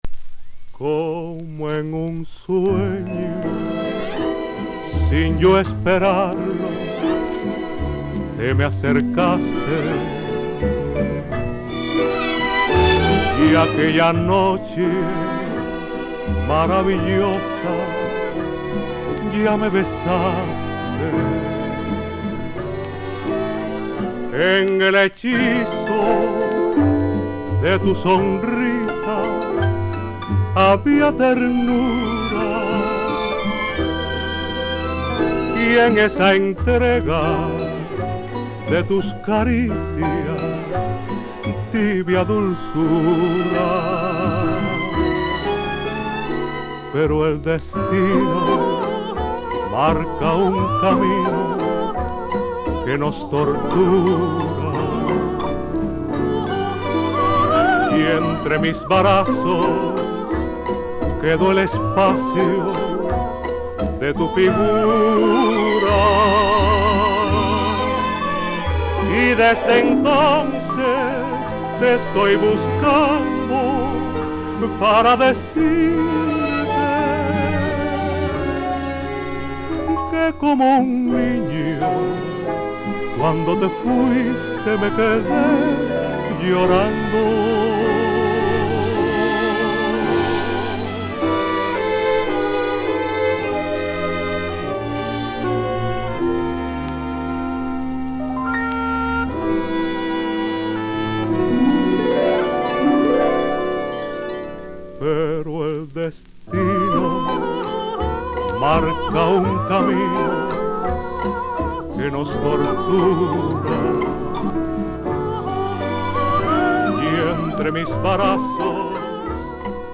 男性ボーカリスト / ボレロ /Bolero
至極のボレロを堪能する事ができる1枚